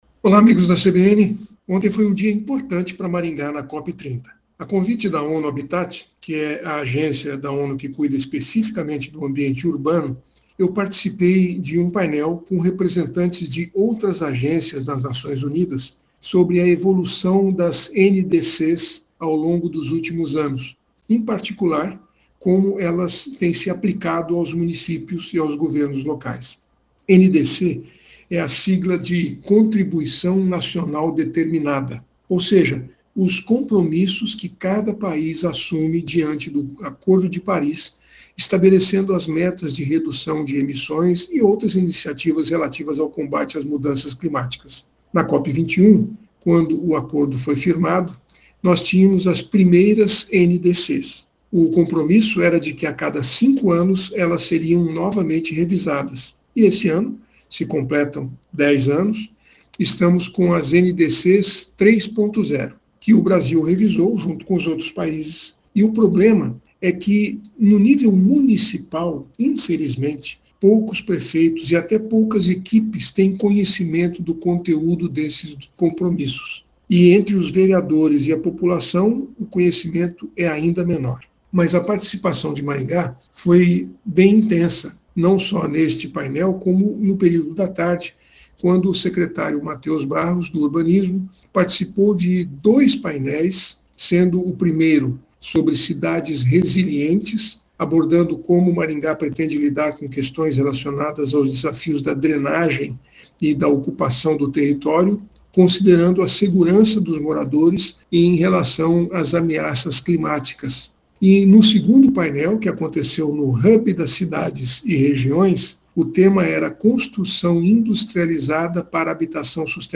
CBN Cidadania e Sustentabilidade, com Silvio Barros, fala sobre atitudes sustentáveis feitos por instituições e pessoas.